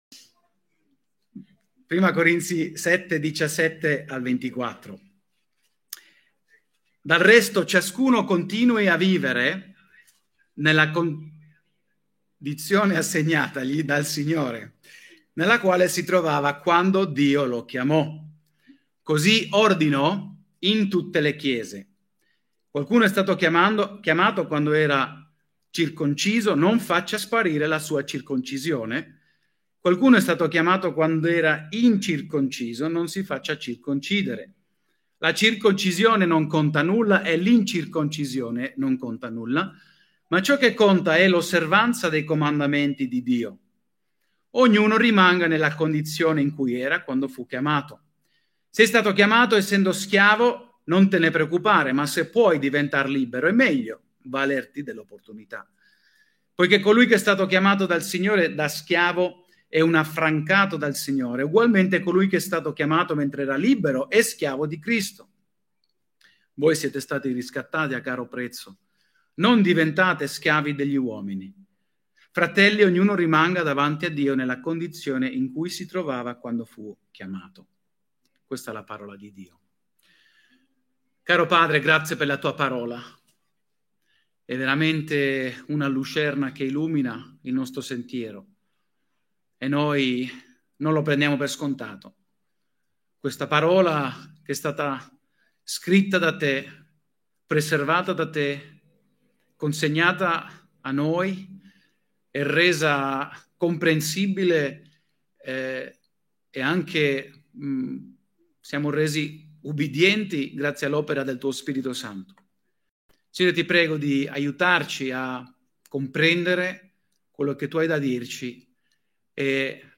Tutti i sermoni